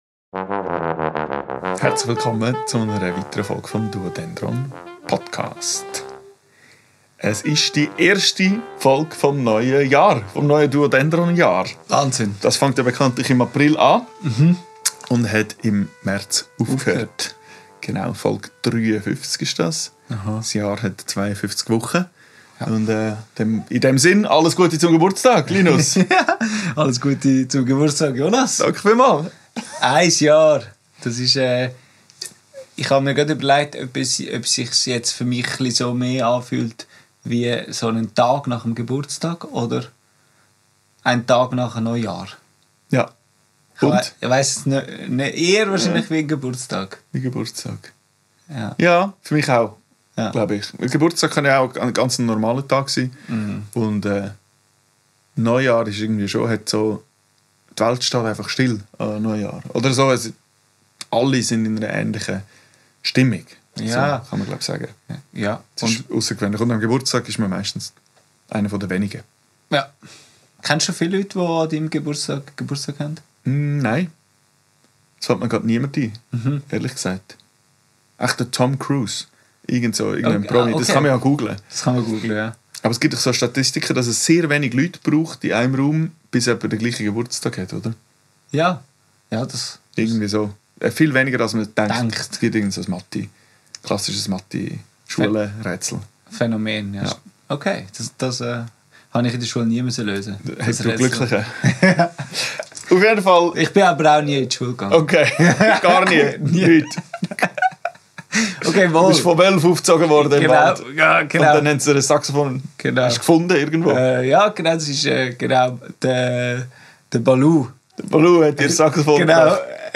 Ein guter Grund, um auf das vergangene Jahr zurückzublicken und zu reflektieren, was alles passiert ist, wie wir uns entwickelt haben und wie es nun weiter gehen könnte. Aufgenommen am 25.03.2025 im Atelier